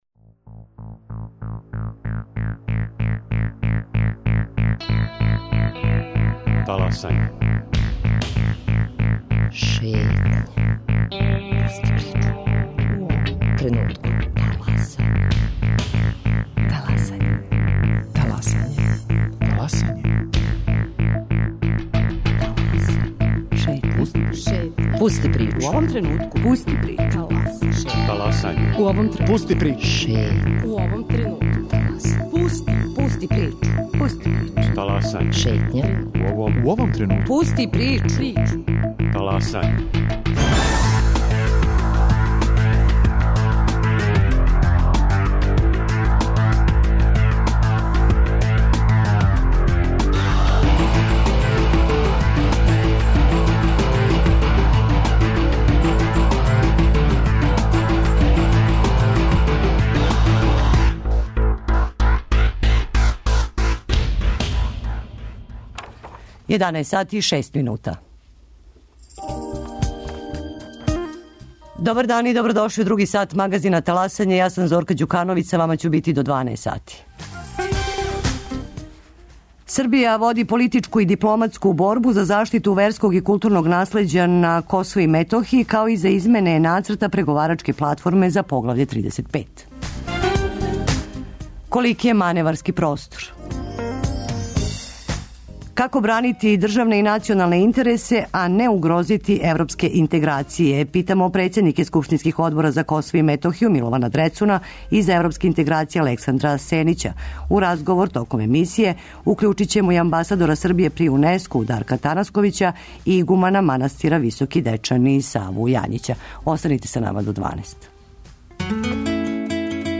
Како бранити државне и националне интересе, а не угрозити европске интеграције питамо председнике скупштинских одбора за Косово и Метохију, Милована Дрецуна и за европске интеграције, Александра Сенића. У разговор ће се укључити амбасадор Србије при Унеску Дарко Танасковић и игуман манастира Високи Дечани Сава Јањић.